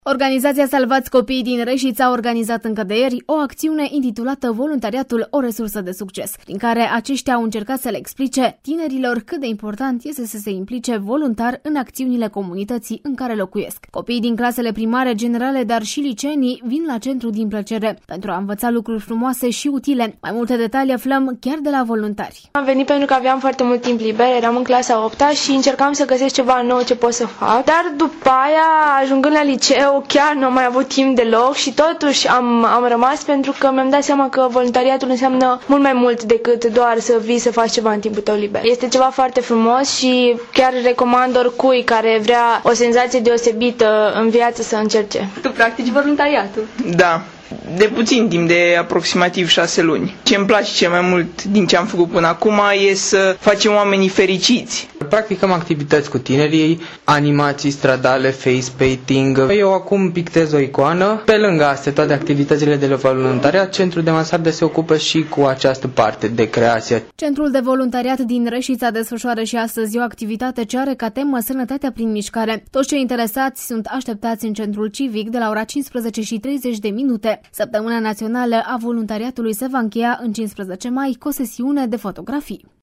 a stat de vorbă cu o parte din voluntarii care îşi desfăşoară activitatea la Centrul Mansarda: